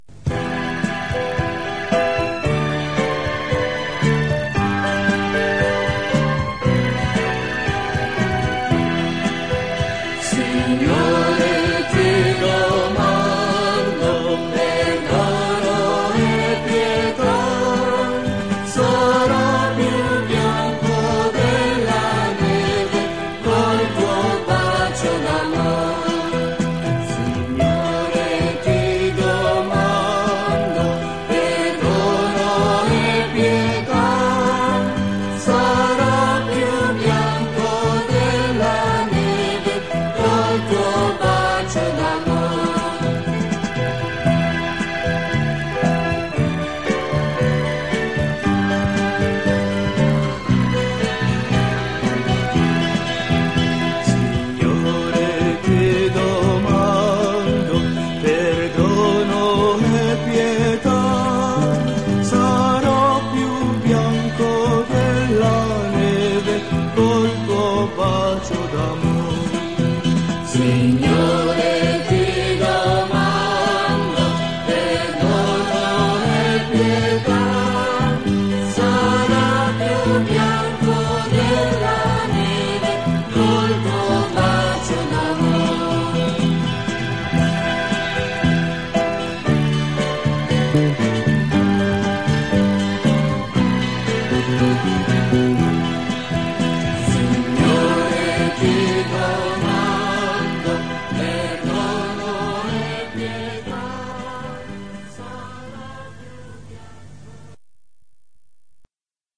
Per eseguire il download (memorizzare sul proprio computer) del canto “Signore, ti domando” (Canto di dolore) fare click con il pulsante destro sul link e dal menu selezionare “Salva link con nome…”.